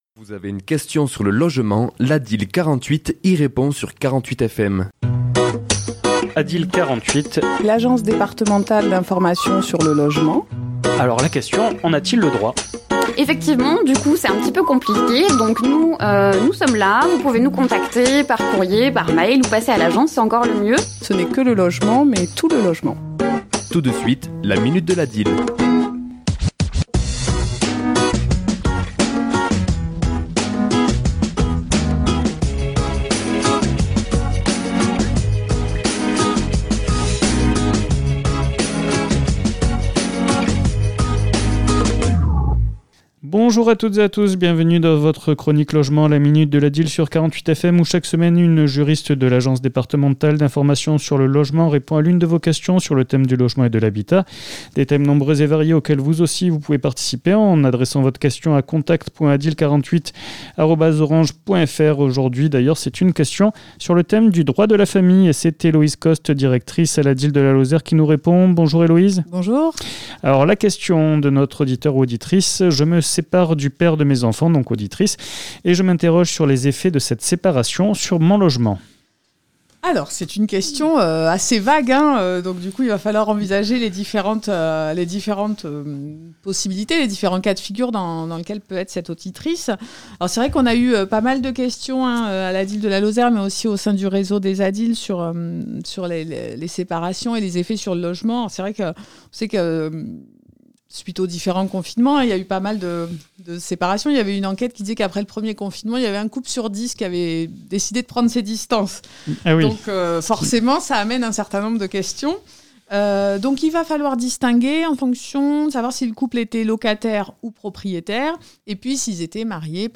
Chronique diffusée le mardi 02 mars à 11h00 et 17h10